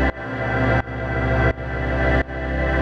Index of /musicradar/sidechained-samples/170bpm
GnS_Pad-dbx1:2_170-C.wav